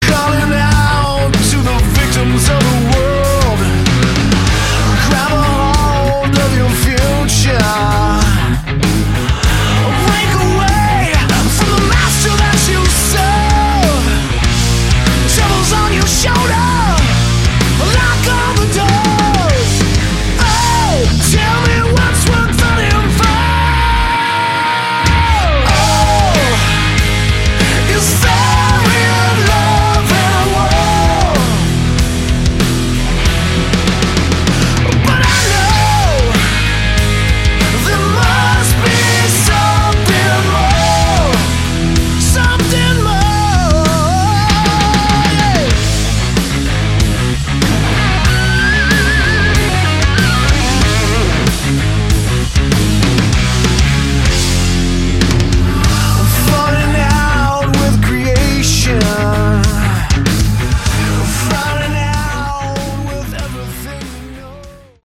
Category: Hard Rock
vocals
drums
bass
guitar